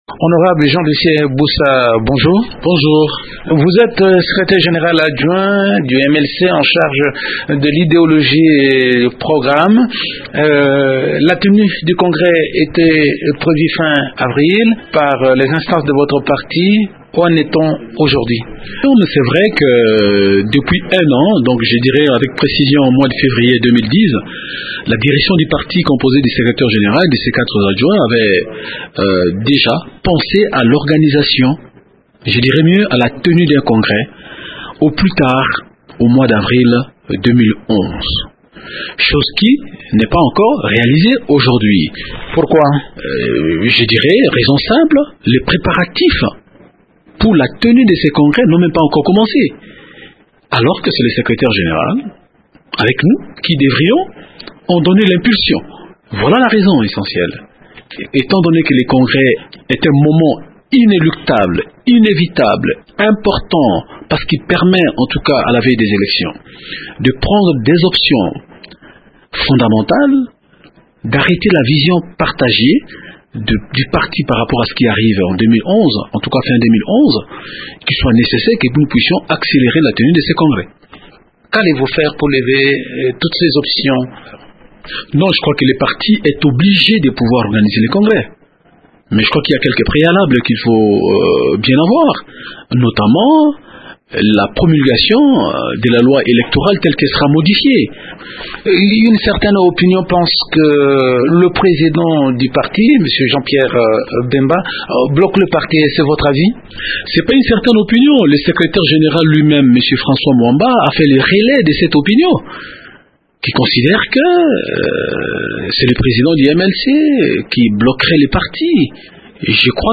Il accuse François Mwamba de prendre fait et cause en faveur de députés MLC qui exigent la levée des options pour les prochaines élections. Le député Jean Lucien Bussa est interrogé